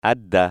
Comme la consonne dans aider